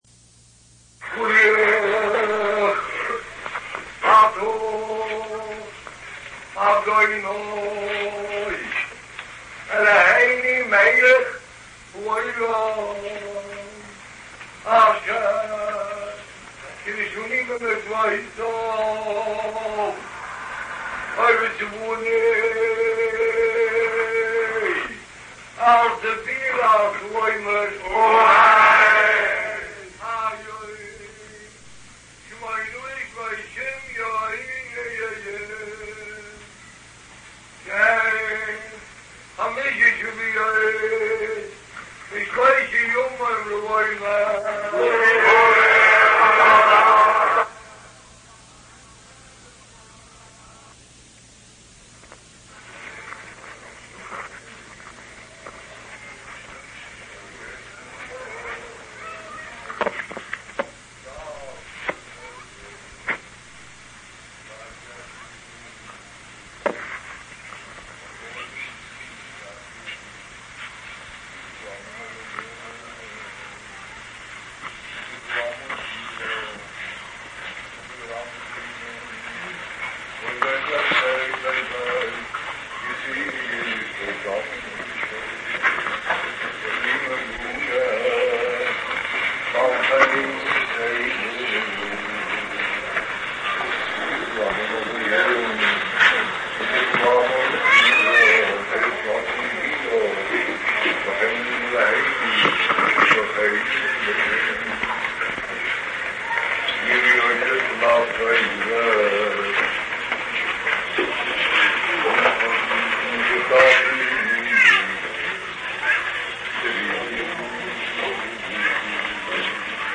ארכיון: קולם בקודש של רבוה"ק בספירת העומר